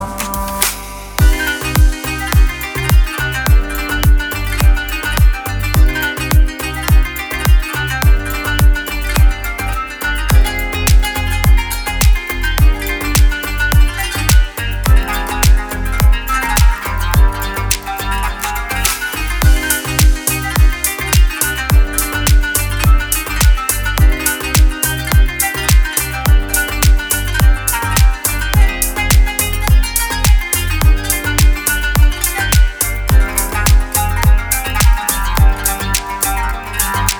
deep house
без слов
красивая мелодия
струнные
восточные
Deep house с восточным мотивом.